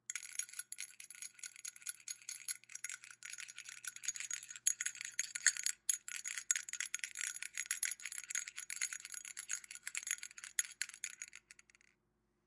闷响
描述：拳击和踢腿的影响可用于游戏或电影。